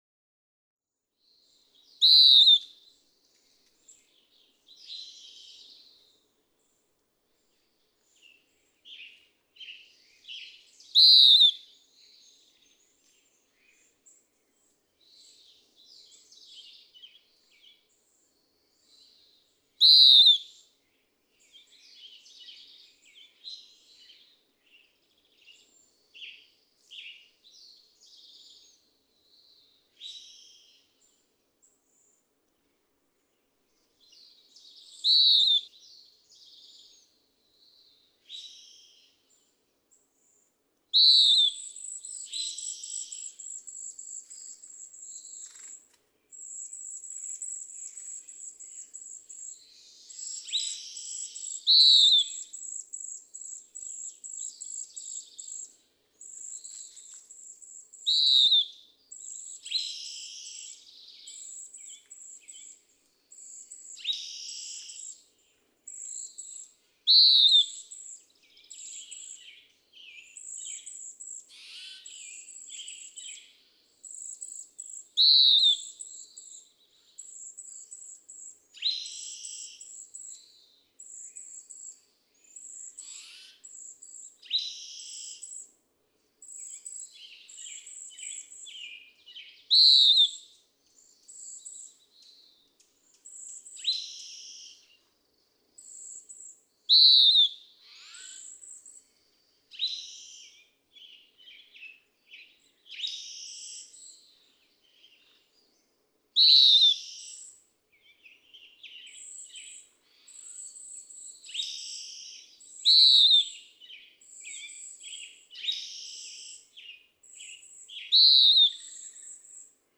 ♫610. Western wood-pewee: Daytime singing, a repeated, burry bzeeyeer. Background: spotted towhee, cedar waxwing (intense calling from 0:41 to 2:28). June 14, 2009. William L. Finley National Wildlife Refuge, Corvallis, Oregon. (2:59)
610_Western_Wood-pewee.mp3